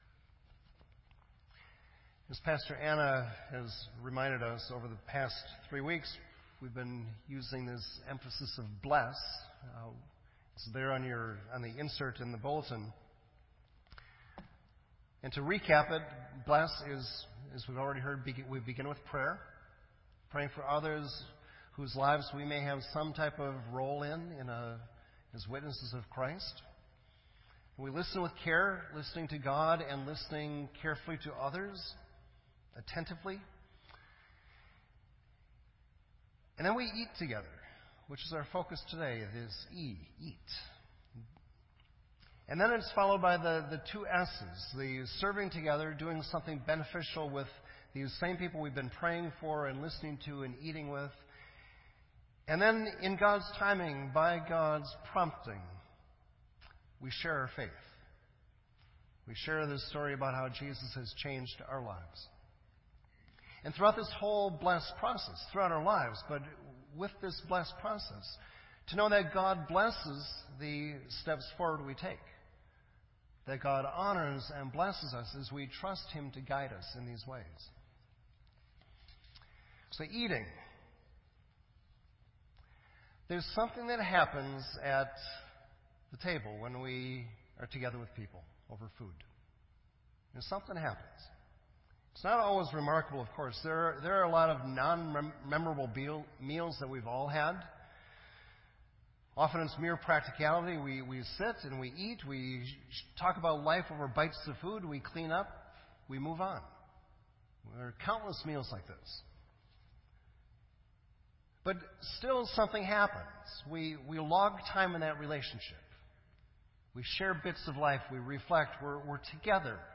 This entry was posted in Sermon Audio on March 16